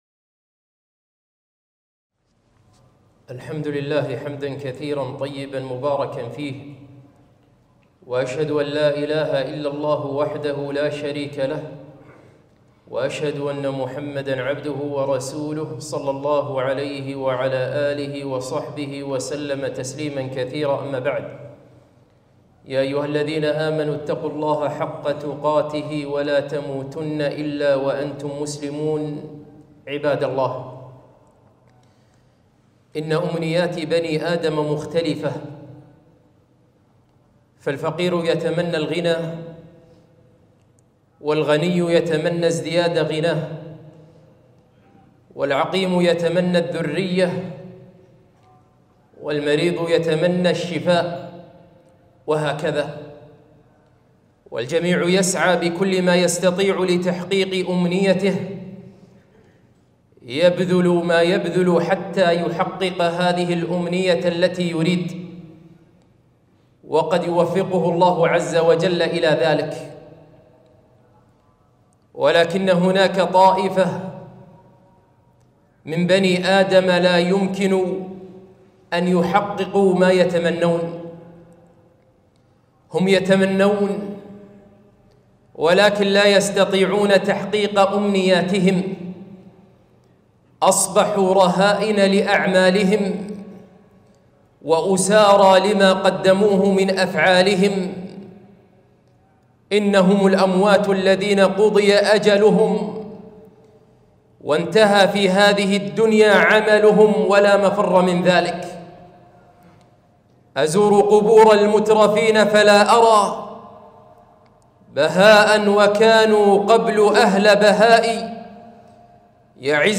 خطبة - أنت في الأمنية فاعملي